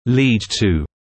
[liːd tuː][лиːд туː]приводить к